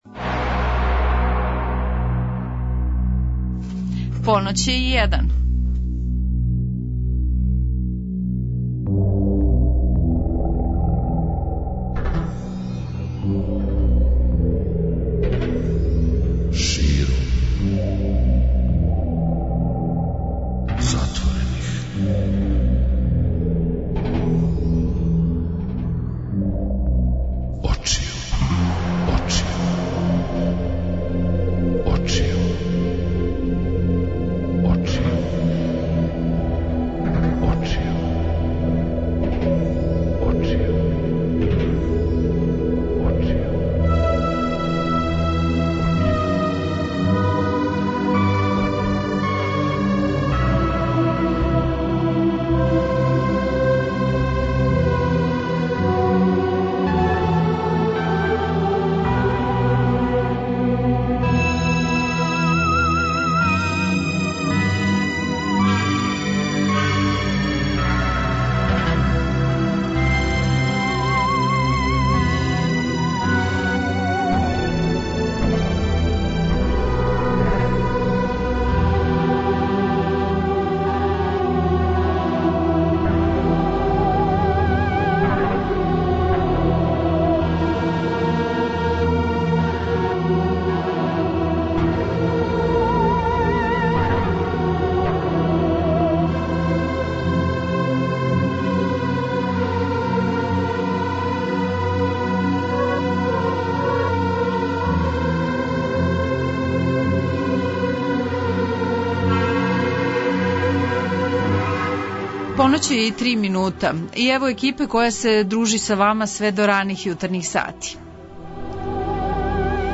Београд 202 Ноћни програм